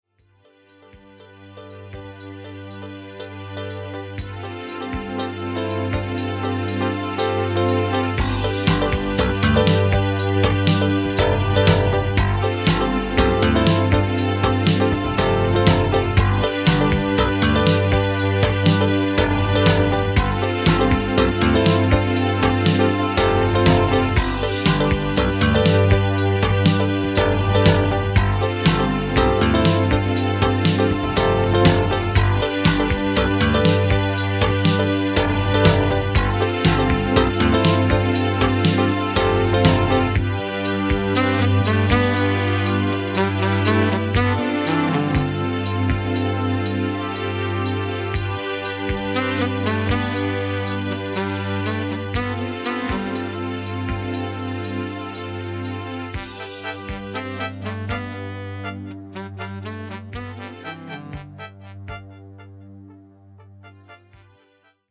Violins will come to perfect the melody and the orchestra…